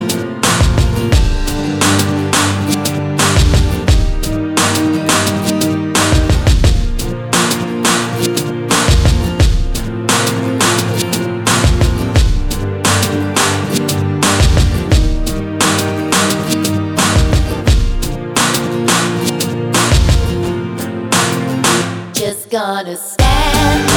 Duet Version Pop (2010s) 4:24 Buy £1.50